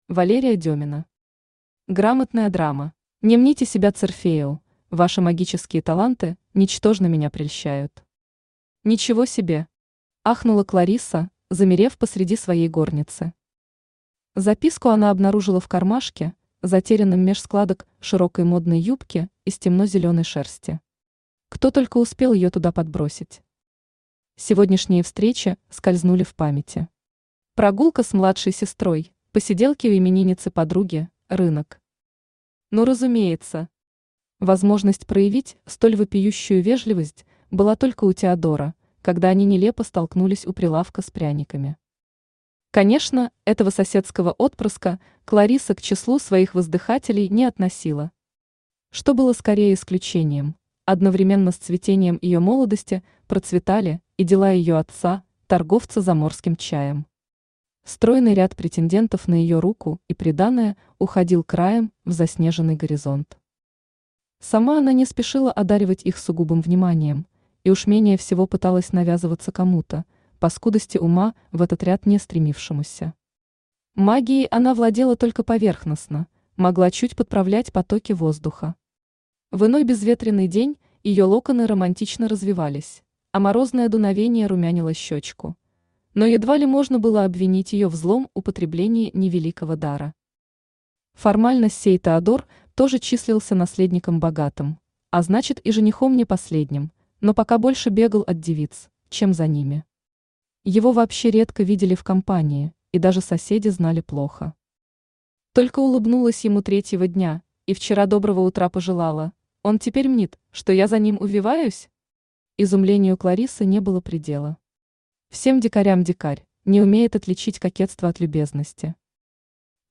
Аудиокнига Грамотная драма | Библиотека аудиокниг
Aудиокнига Грамотная драма Автор Валерия Демина Читает аудиокнигу Авточтец ЛитРес.